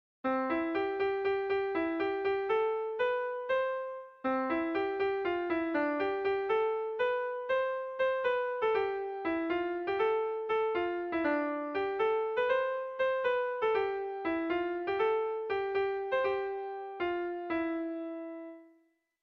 Irrizkoa
Zortziko berezia, 5 puntuz (hg) / Bost puntuko berezia (ip)
AAB1B2